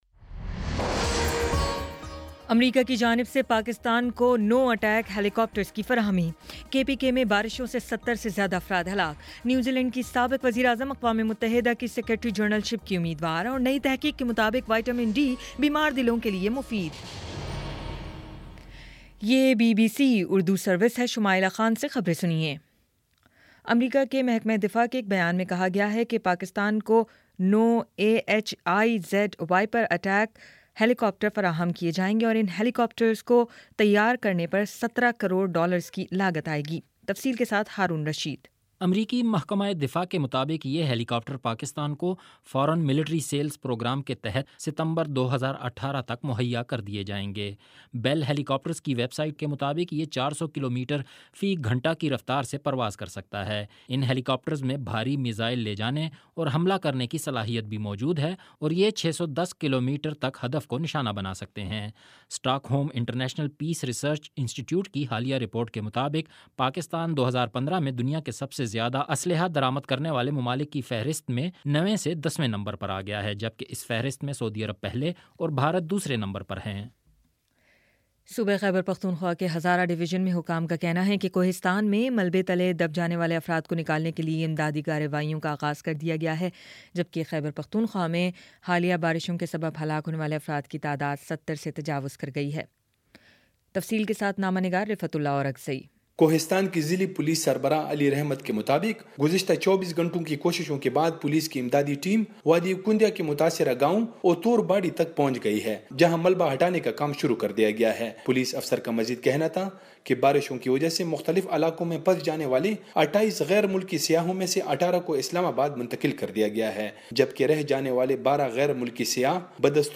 اپریل 05 : شام چھ بجے کا نیوز بُلیٹن